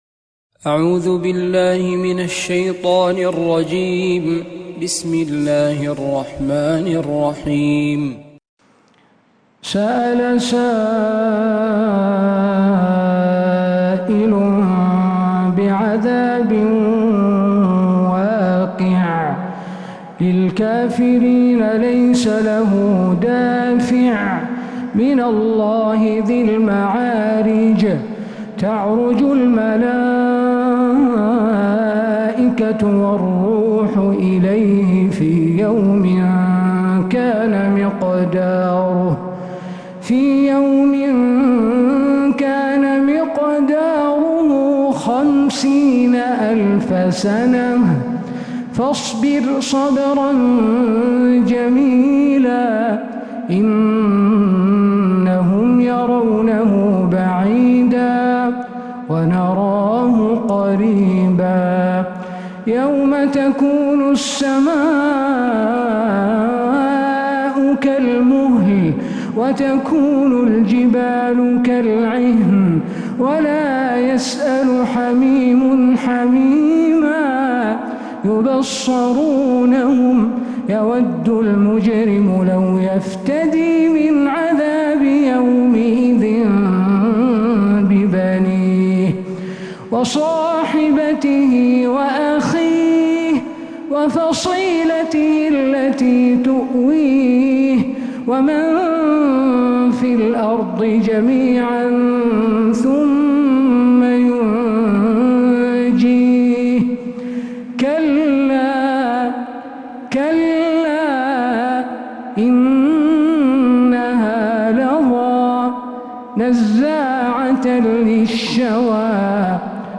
سورة المعارج كاملة من عشائيات الحرم النبوي